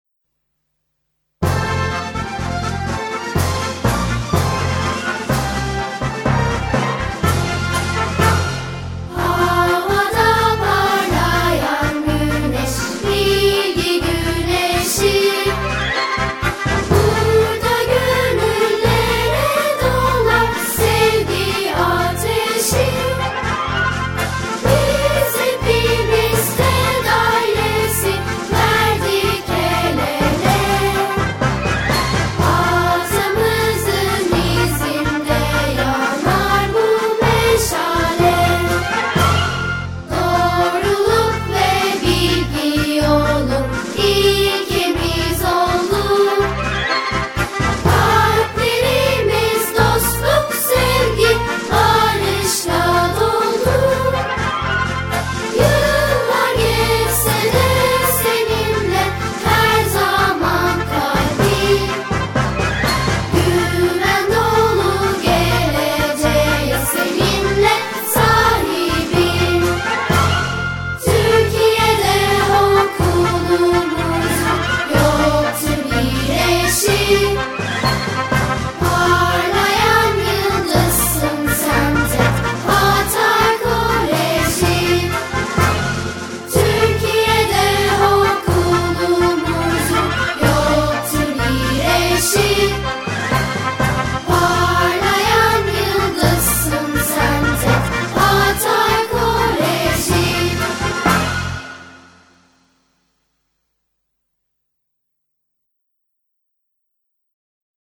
koro.mp3